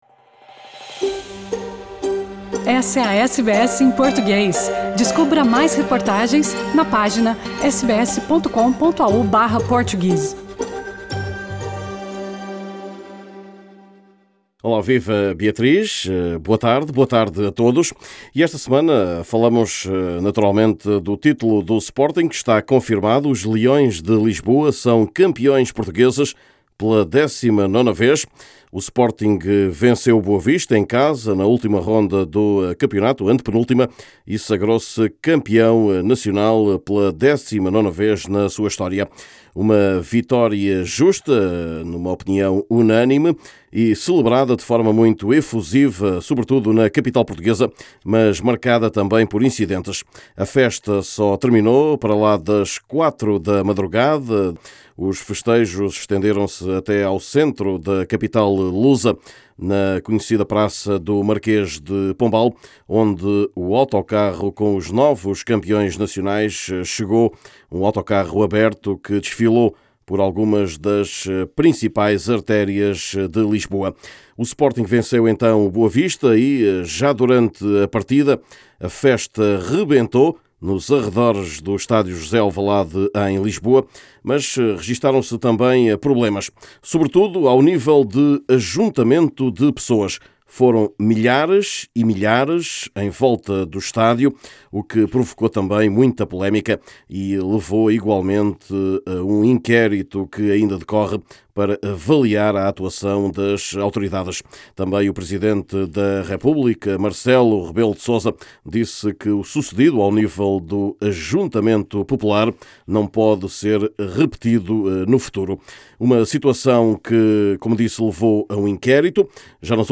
Neste boletim semanal, lugar ainda à (nova) escolha do país para a final da Liga dos Campeões, desta vez será na cidade do Porto.